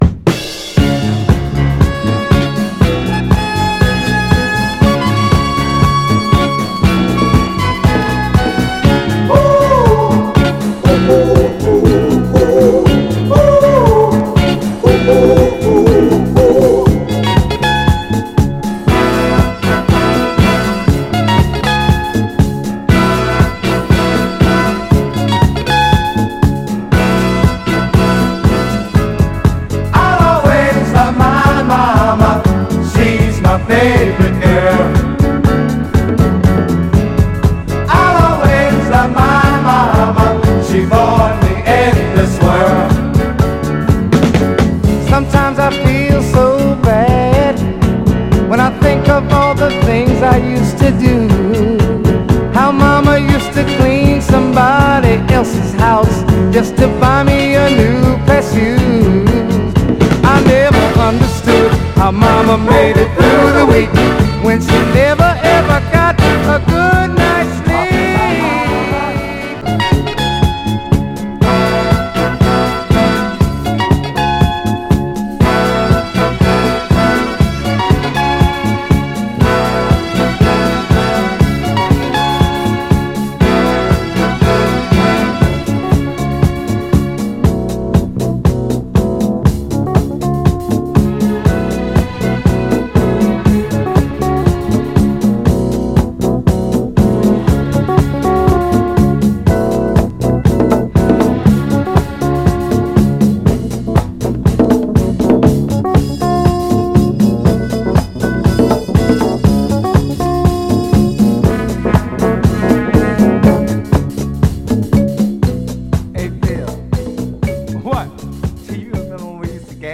完成度の高いフィリー・ディスコの雛形が炸…